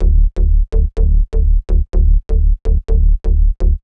cch_bass_loop_mania_125_Dm.wav